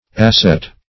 Asset \As"set\, n.